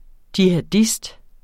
Udtale [ djihaˈdisd ]